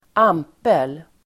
Ladda ner uttalet
Uttal: ['am:pel]